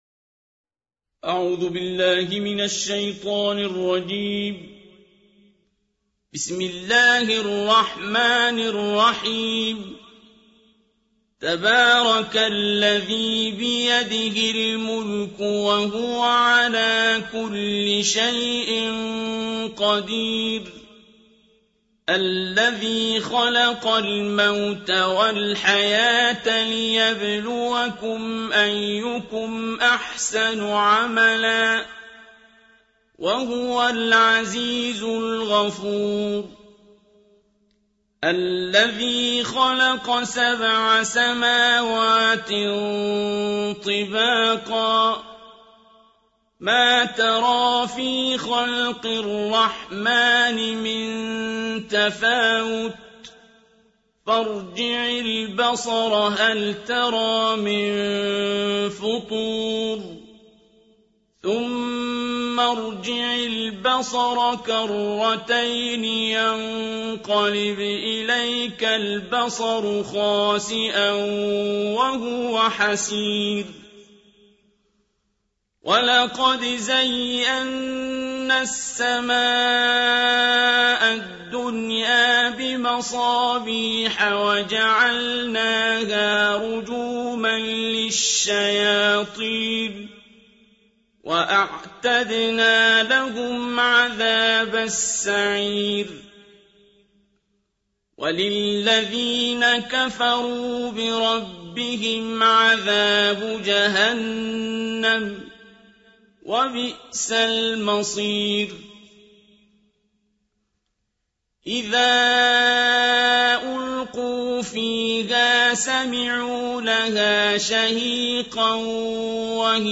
ترتیل جزء بیست‌ونهم قرآن‌